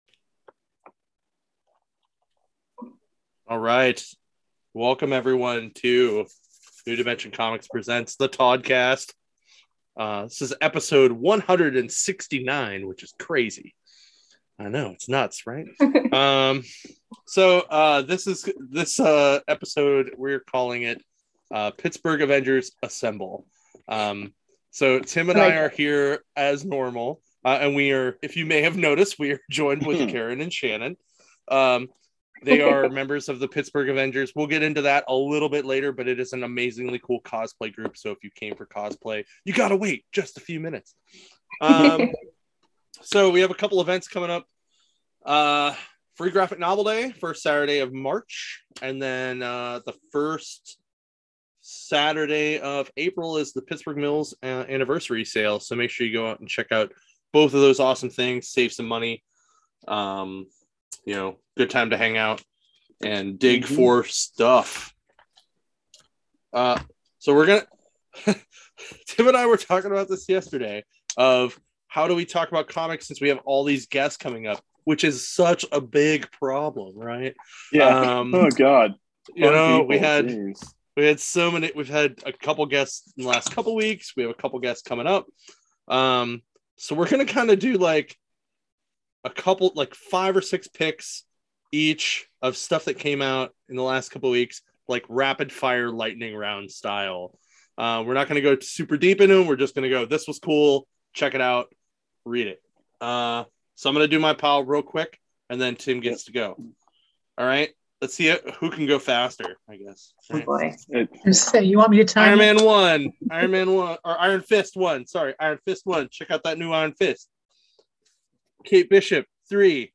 Many laughs are had.